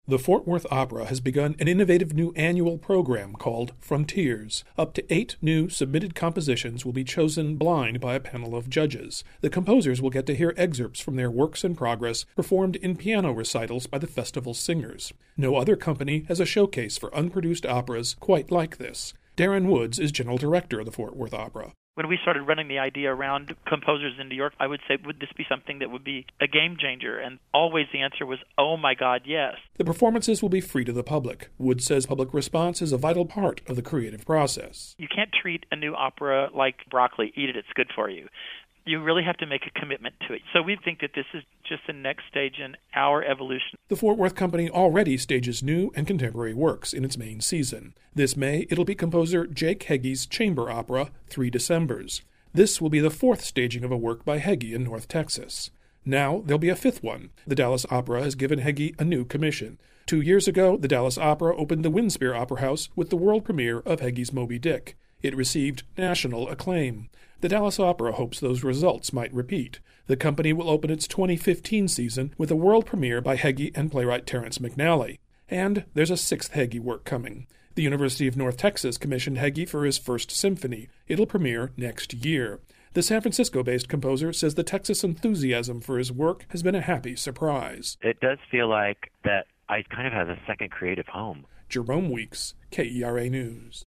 KERA radio story: